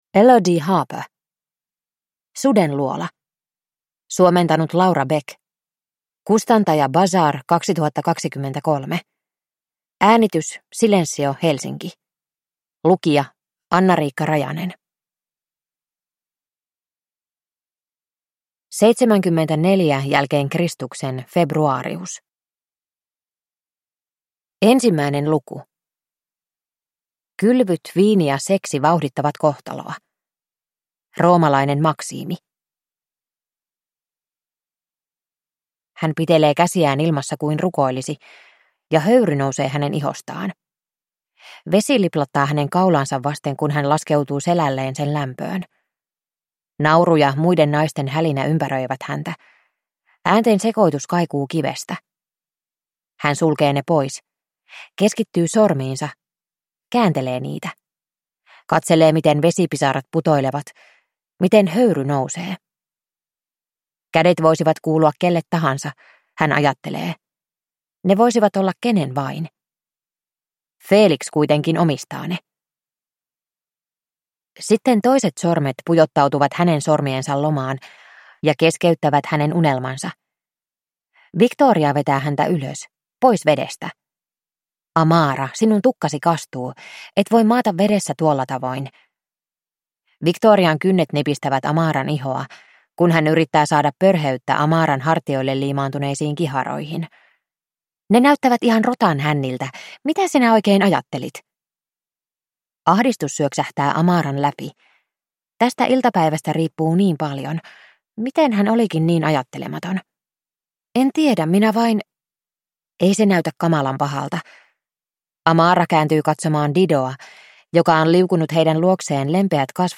Sudenluola – Ljudbok – Laddas ner